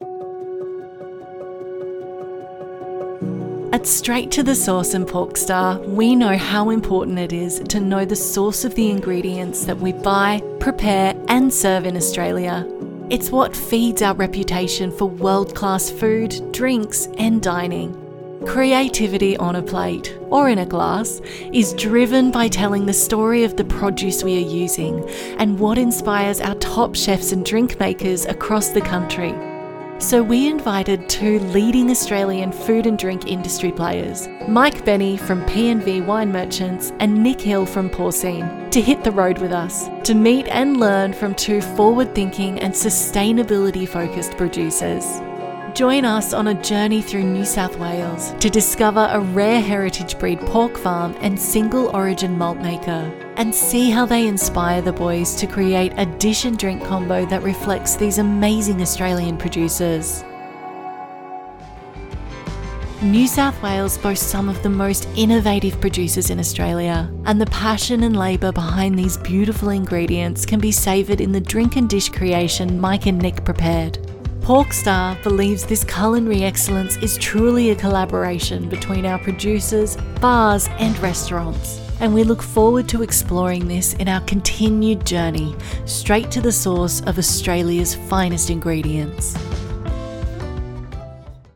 Female
English (Australian)
Yng Adult (18-29), Adult (30-50)
Commercial Reel
All our voice actors have professional broadcast quality recording studios.